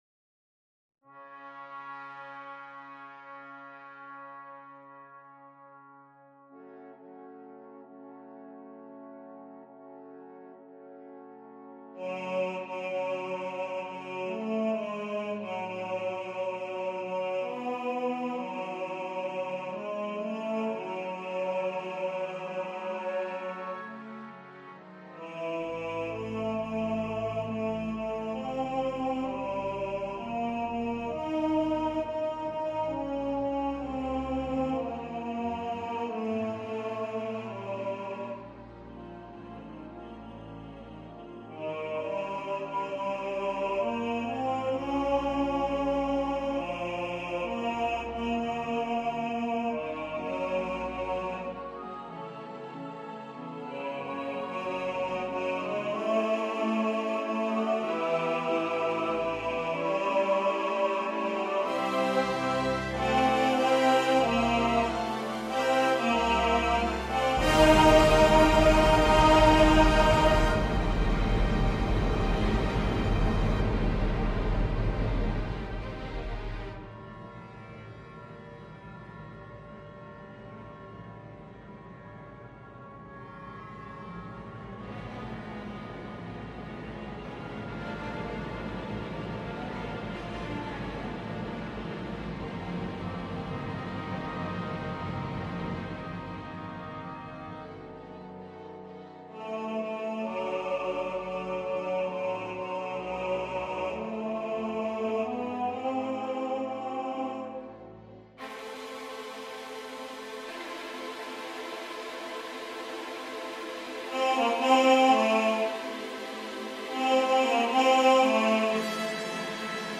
In fact they'll sound rather tinny, but then they're just a rehearsal aid. You'll hear your part, with the other parts faintly in the background so you can get a feel for the harmonies.
There will usually be no dynamics, pauses, rits etc. You'll sometimes hear a little of the accompaniment, usually just the introduction or key phrases.
Requiem08_LiberaM_Bass.mp3